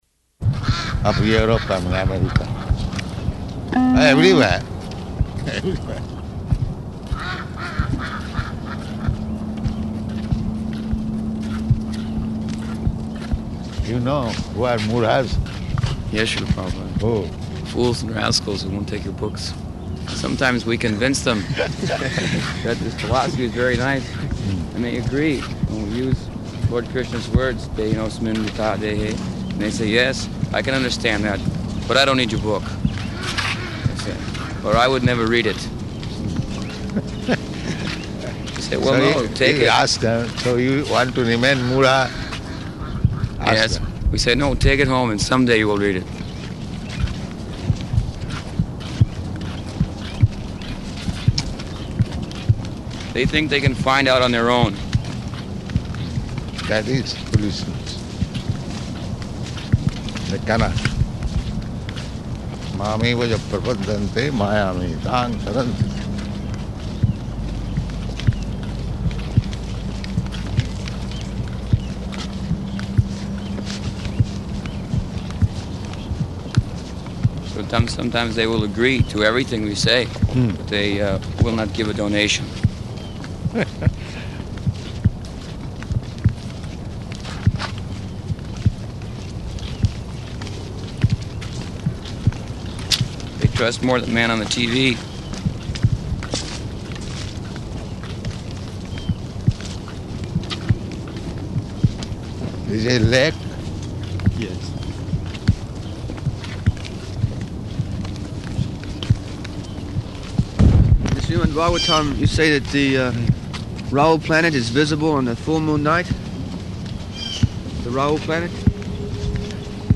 Morning Walk --:-- --:-- Type: Walk Dated: March 1st 1975 Location: Atlanta Audio file: 750301MW.ATL.mp3 Prabhupāda: ...of Europe and of America.